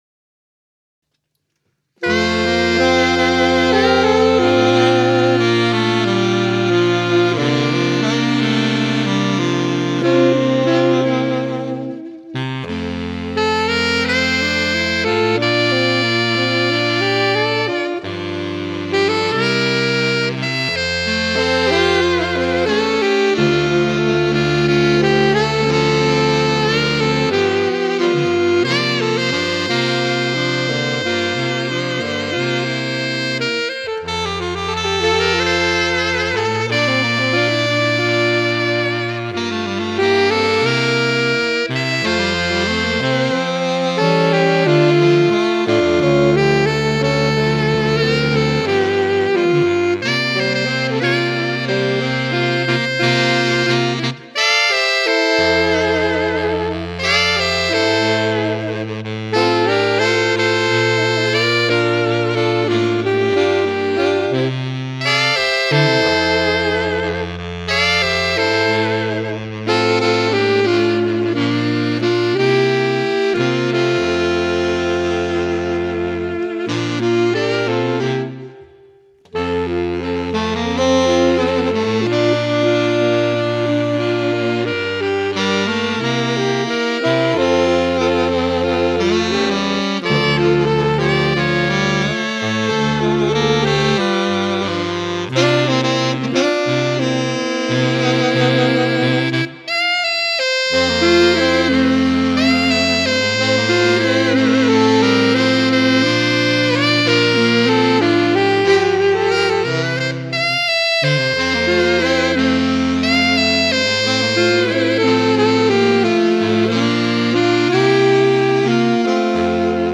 1980’s pop